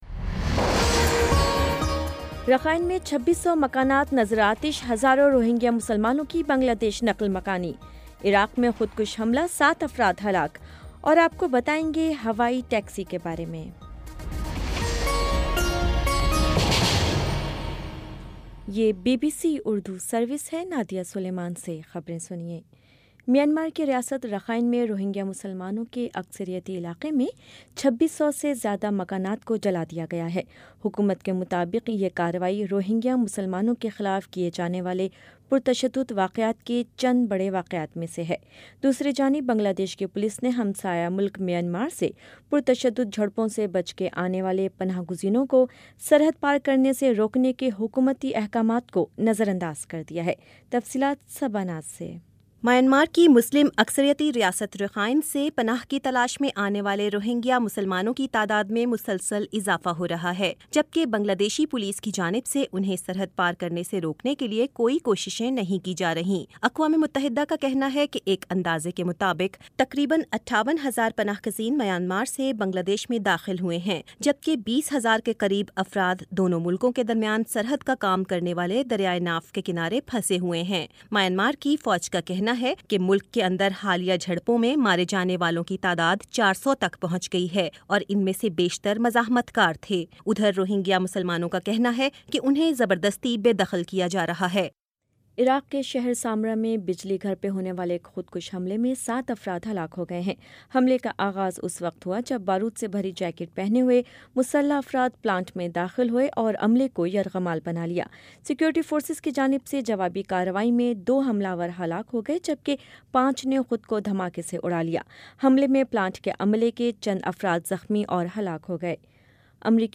ستمبر 02 : شام سات بجے کا نیوز بُلیٹن